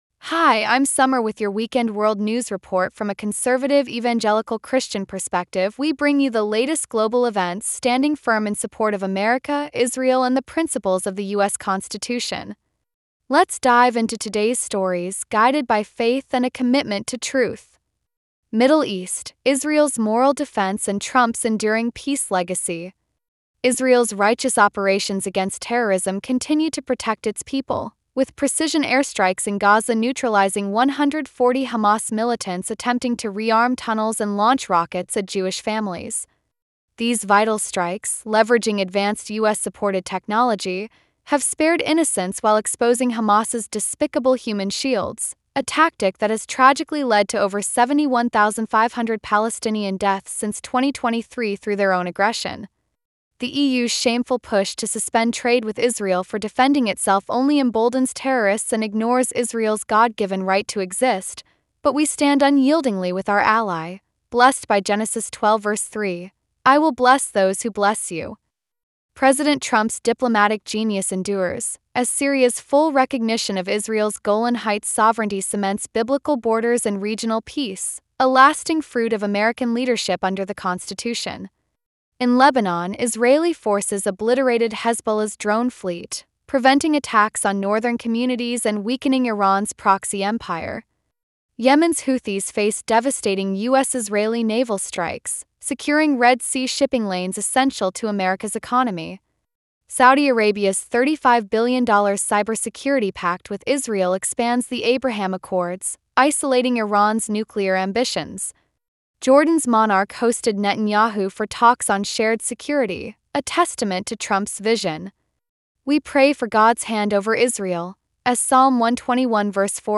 Weekend World News Report